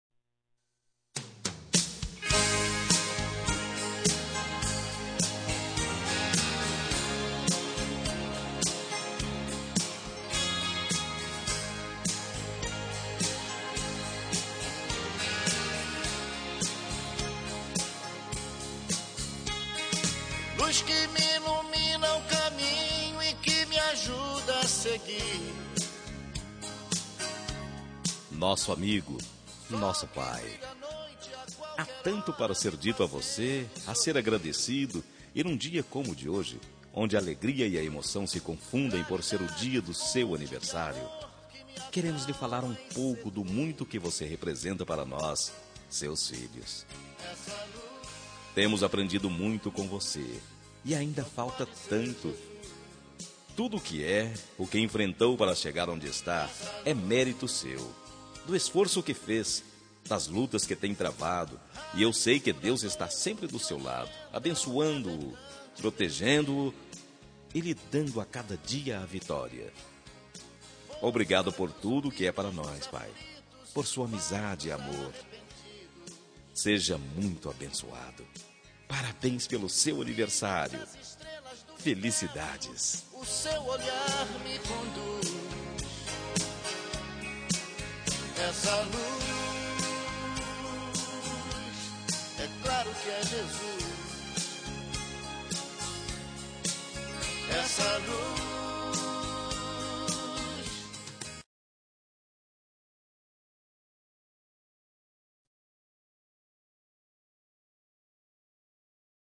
Telemensagem de Aniversário de Pai – Voz Masculina – Cód: 1510 Religiosa